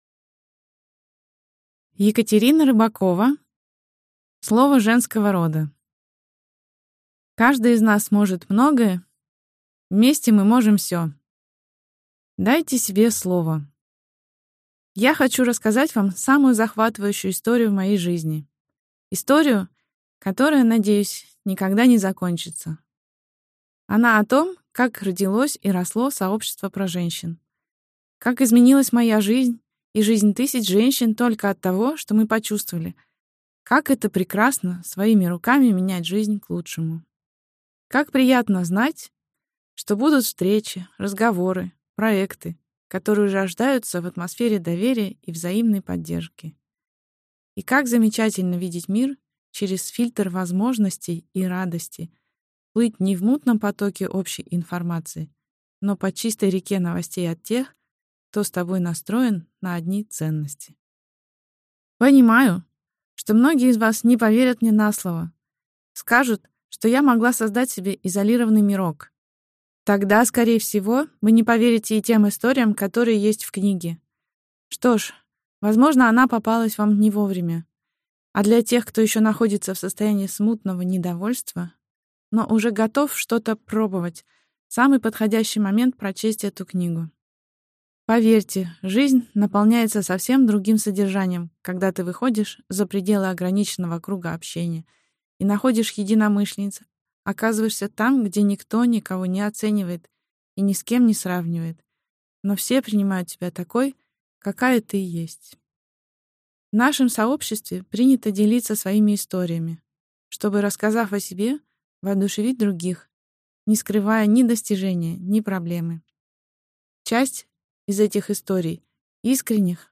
Аудиокнига Слово женского рода | Библиотека аудиокниг